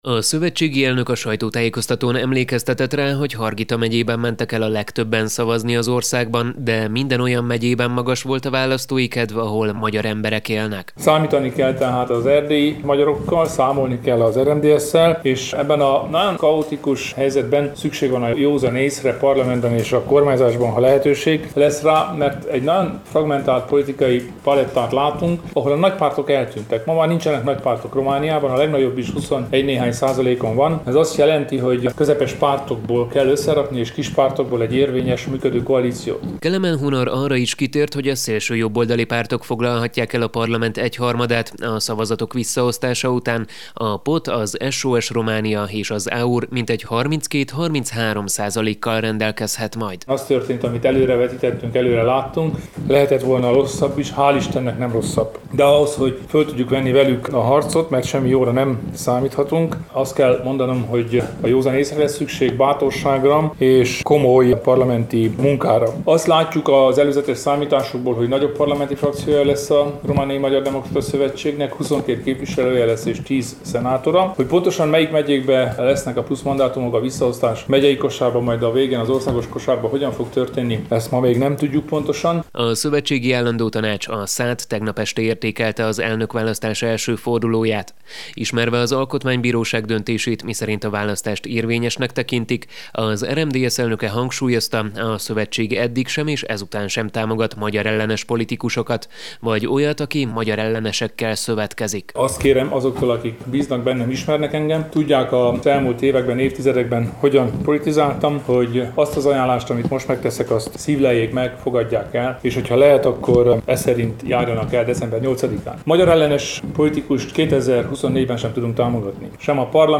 Ezt követően tartott sajtótájékoztatót Kolozsváron Kelemen Hunor az RMDSZ elnöke.